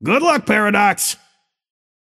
Shopkeeper voice line - Good luck, Paradox.
Shopkeeper_hotdog_t4_paradox_03.mp3